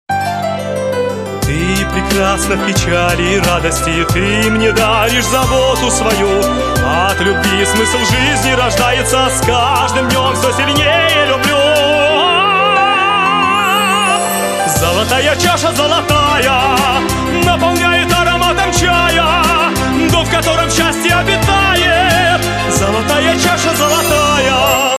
Романтические рингтоны
Громкие рингтоны